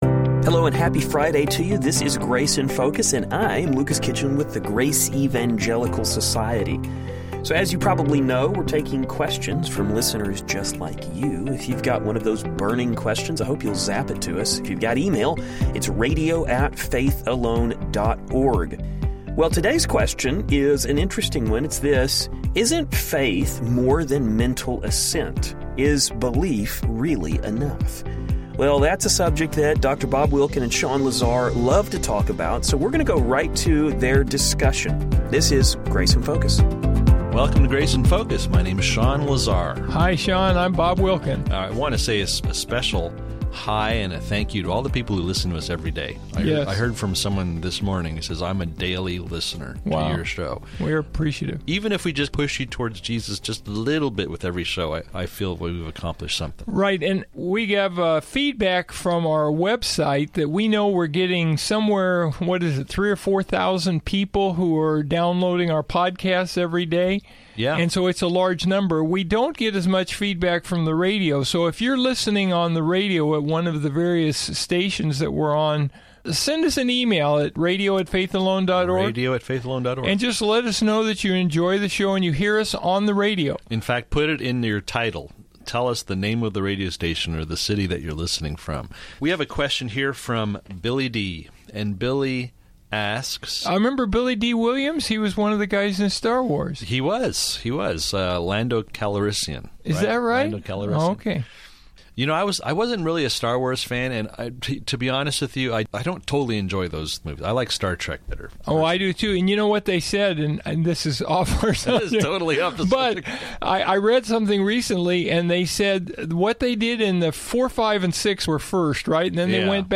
Over the last few weeks, we have been answering our listeners questions.